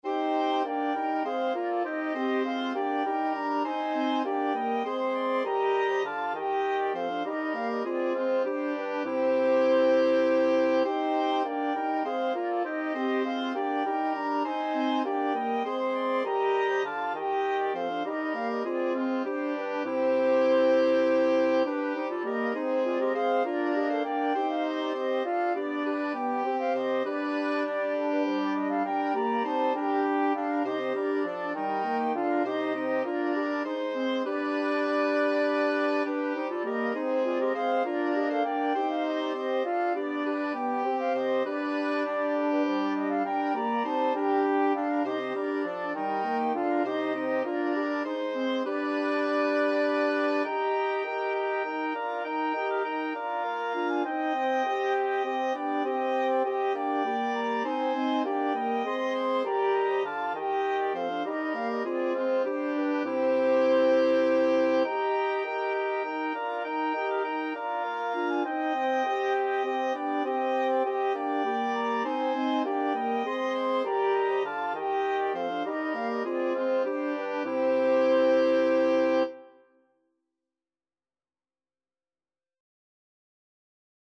3/2 (View more 3/2 Music)
Wind Quintet  (View more Intermediate Wind Quintet Music)
Classical (View more Classical Wind Quintet Music)